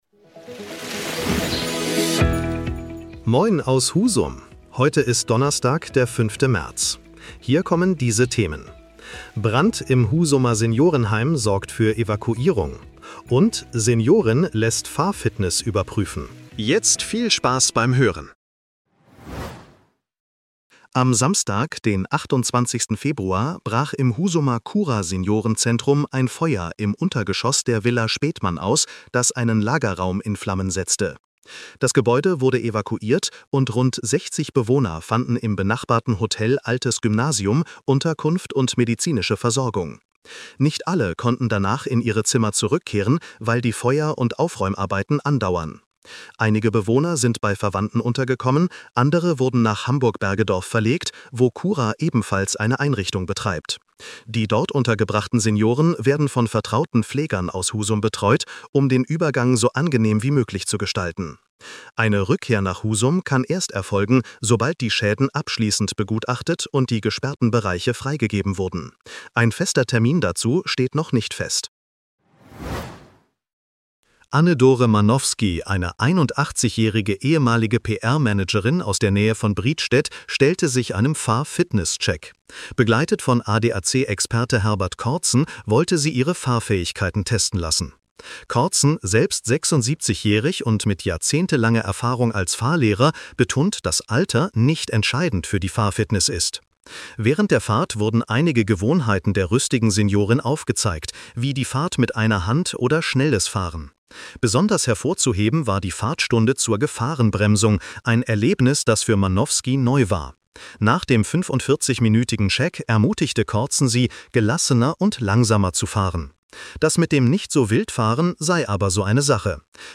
Was bewegt Husum heute? In unserem regionalen Nachrichten-Podcast